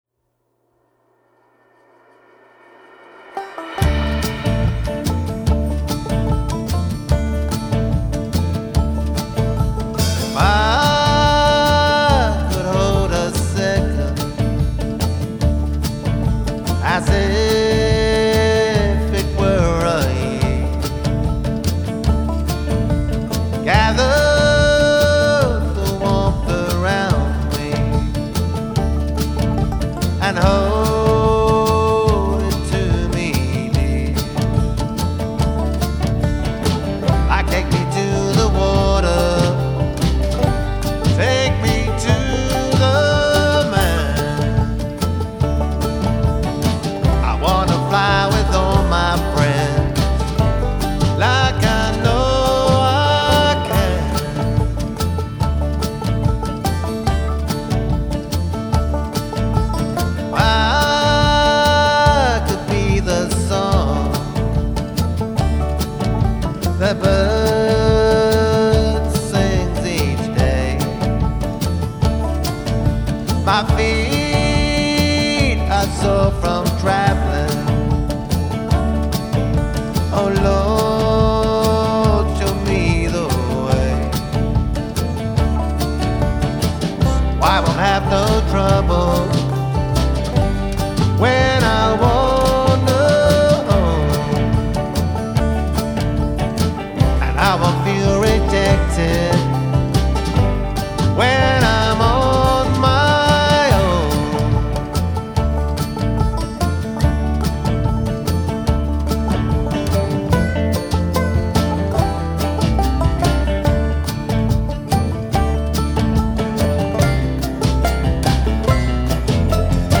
Hillbilly/Roots Country